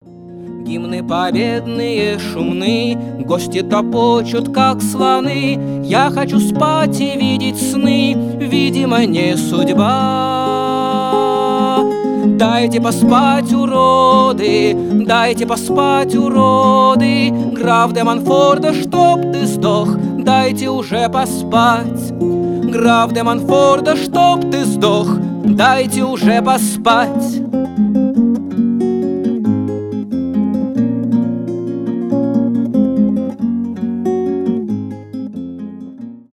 прикольные , смешные
гитара
авторская песня